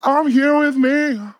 Categories: Vocals
MAN-LYRICS-FILLS-120bpm-Am-8.wav